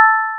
chat_notify.wav